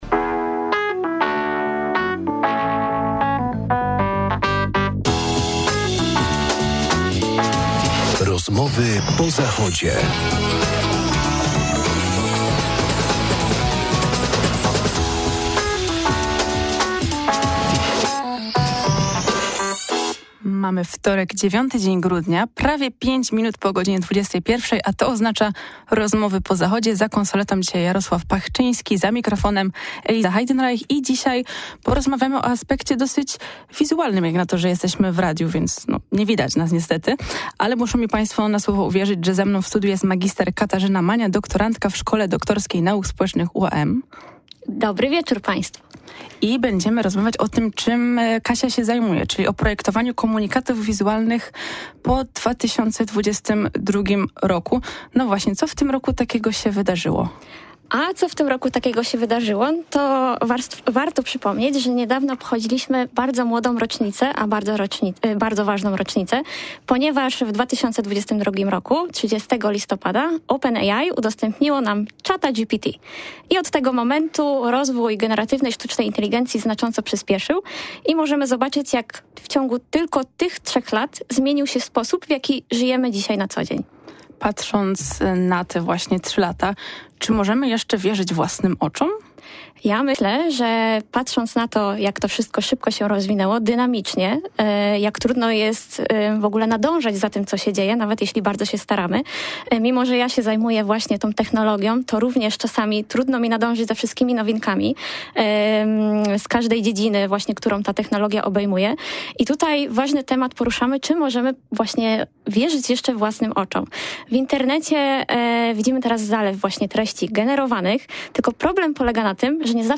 W studiu gościliśmy